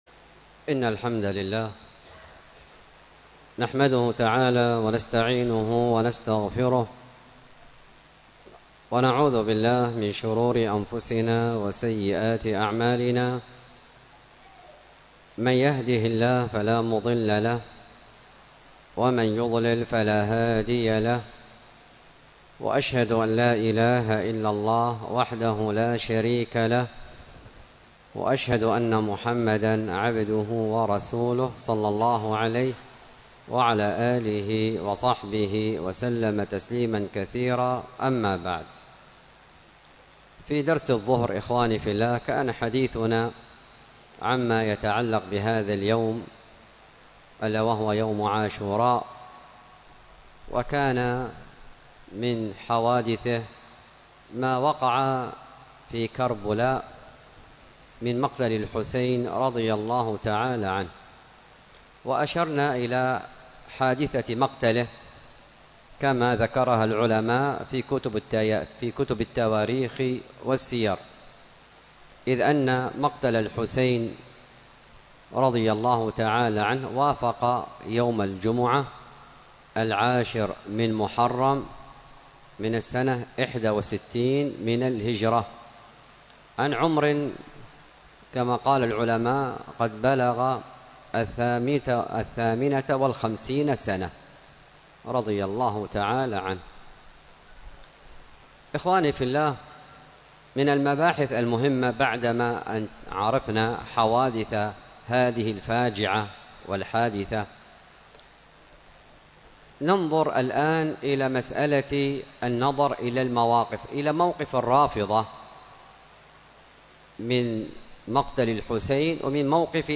المحاضرة بعنوان فضيلة عاشوراء، والتي كانت بمسجد التقوى بدار الحديث بالشحر